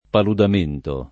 paludamento [ paludam % nto ]